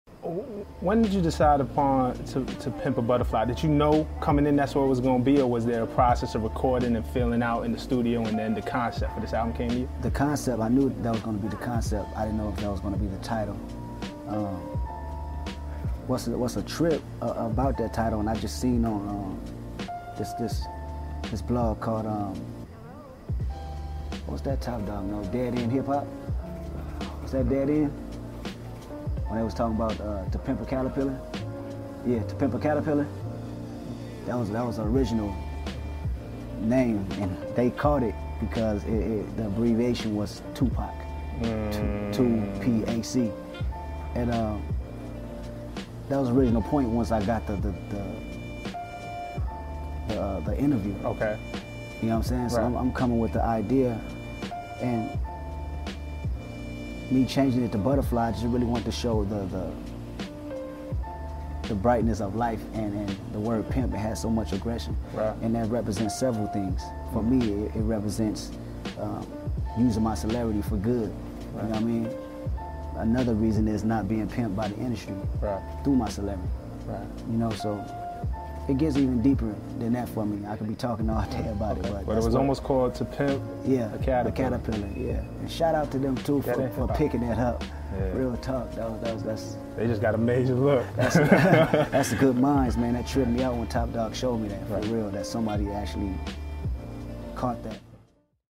Kendrick Lamar Shouts Out Dead End Hip Hop in MTV Interview!